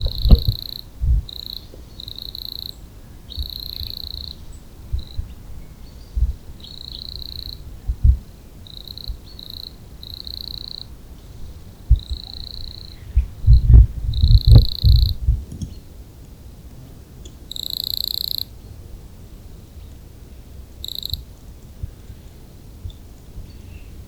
Gesang der Männchen: mäßig laute Verse, die in unterschiedlichen Abständen aneinander gereiht werden.
Feldaudioaufnahme. Bad Muskau, 03.10.2020. Zoom H6, SGH-6 Shotgun Mic Capsule, in der Lautstärke verstärkte Aufnahme.
1026_0_N_sylvestris.wav